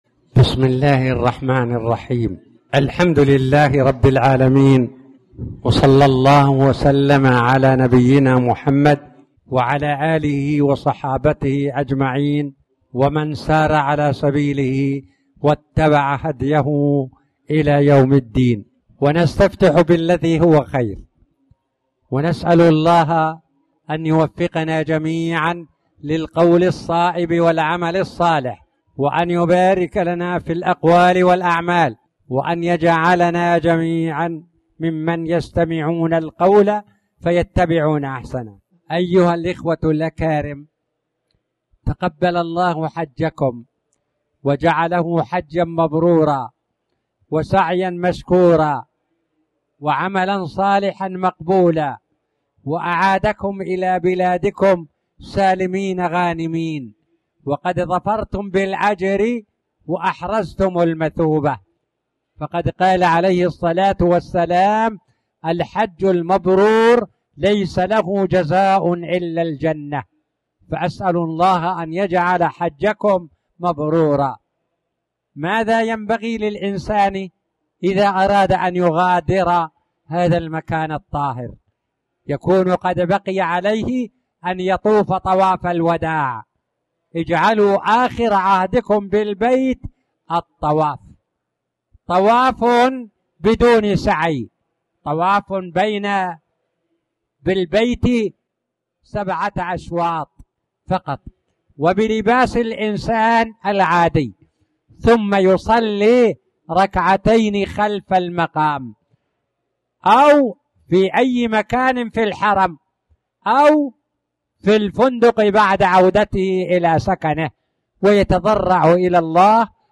تاريخ النشر ٢٦ ذو القعدة ١٤٣٨ هـ المكان: المسجد الحرام الشيخ